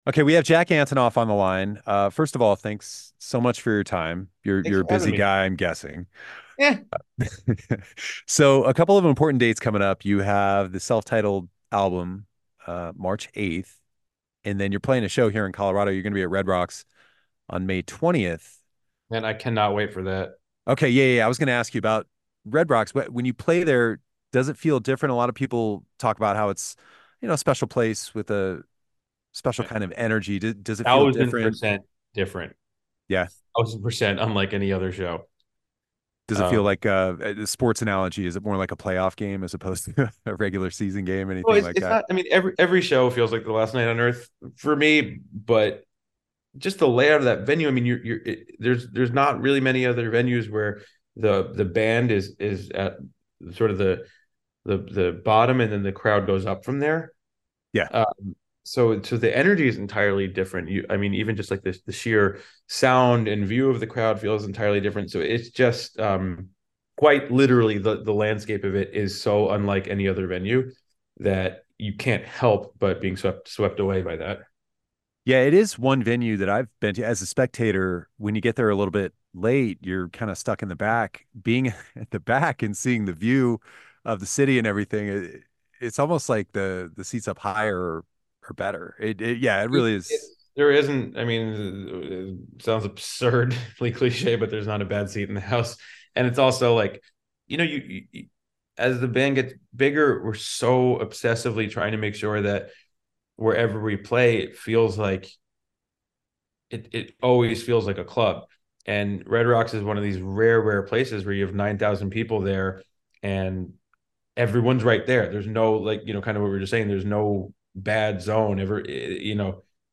Play Rate Listened List Bookmark Get this podcast via API From The Podcast Indie 102.3 Sessions features performances and interviews from national and Colorado musicians in the Colorado Public Radio Performance Studio.
Jack-Antonoff-interview-edited.mp3